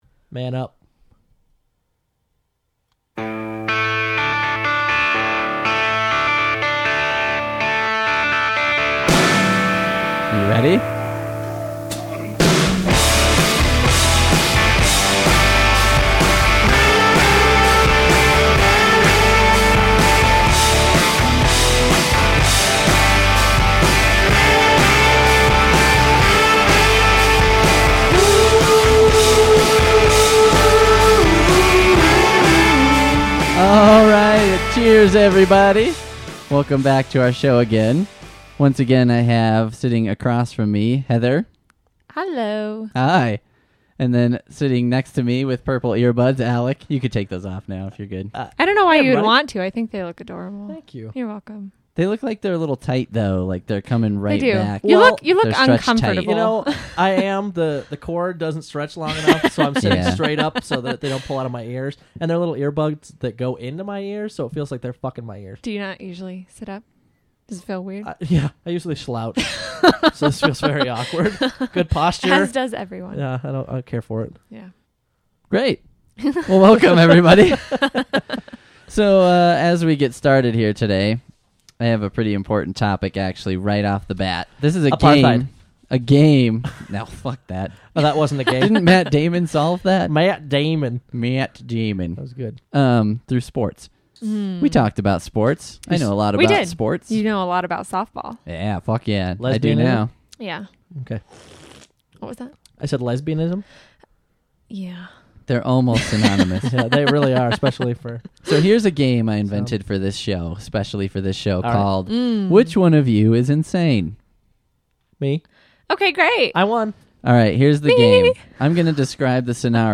Lots of yelling on this show.